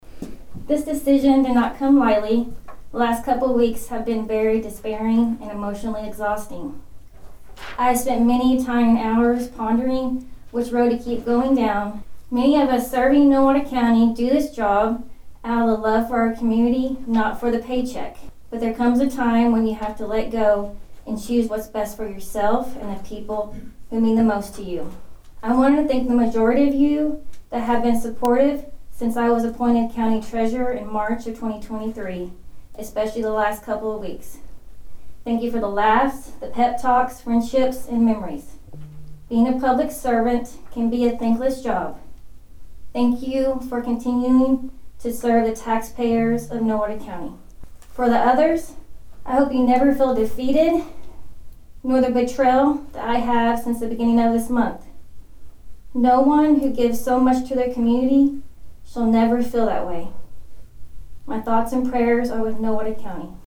The Nowata County Commissioners held a regularly scheduled meeting at the Nowata County Annex Monday morning.
Marrs gave the following statement before the board.
Rachel Mars Resignation Statement.mp3